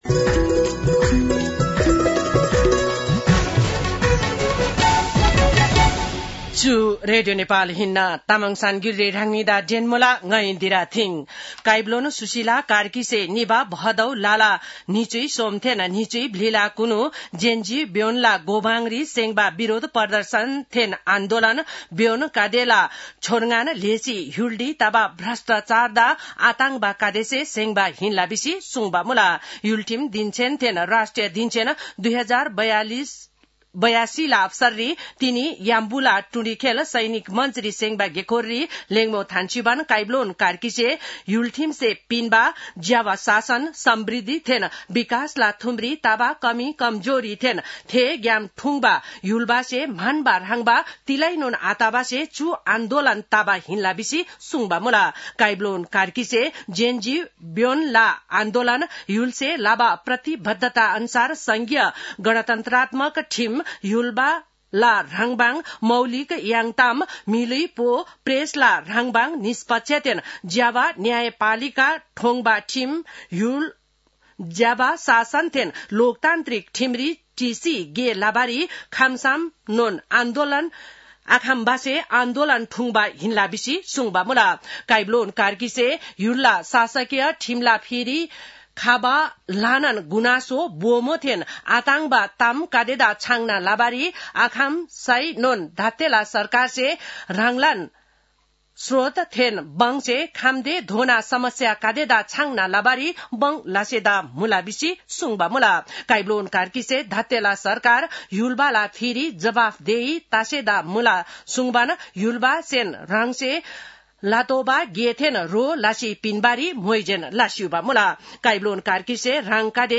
तामाङ भाषाको समाचार : ३ असोज , २०८२